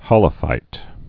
(hŏlə-fīt)